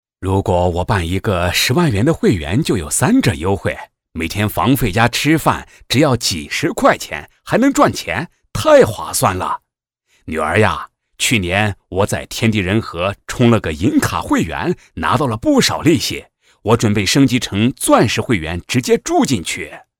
角色对话 | 声腾文化传媒
【角色】大爷男14.mp3